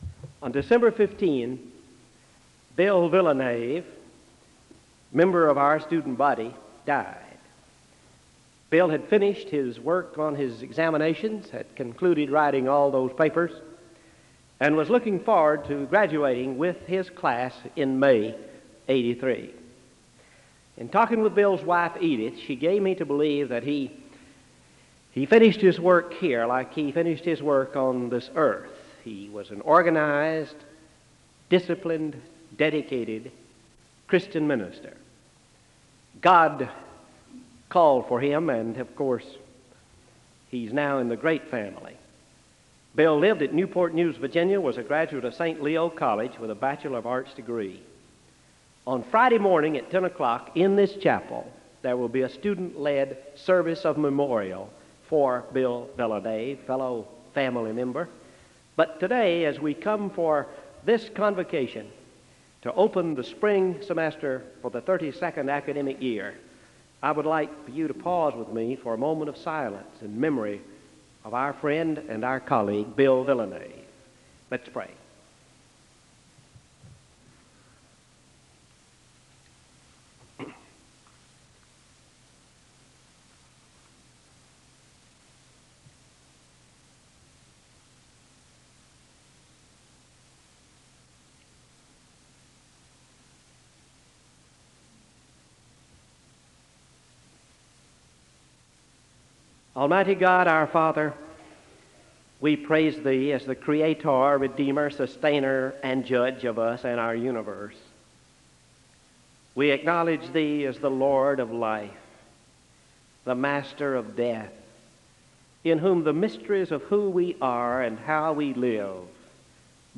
Remembrance and moment of silence for a student from Southeastern who passed away in December followed by a prayer (0:00-5:00).
Introduction of the visiting and adjunctive faculty for the semester (13:00-14:23).